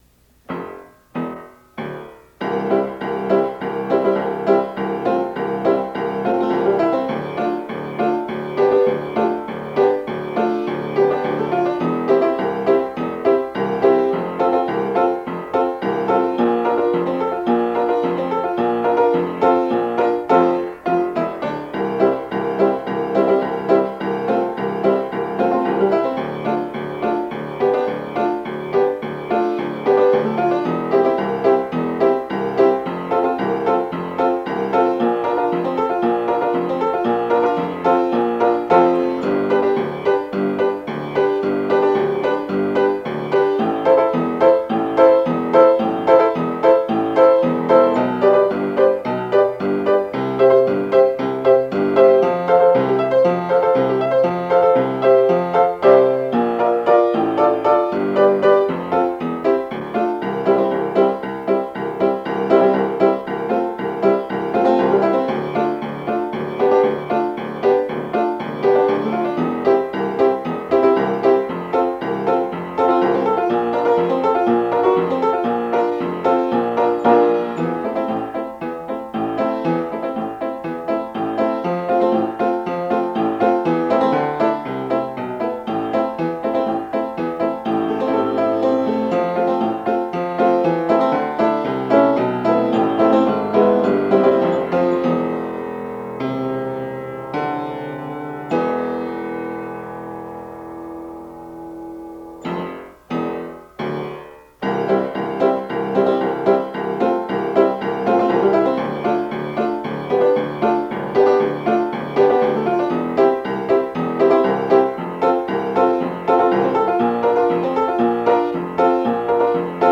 The words are spoken, not sung.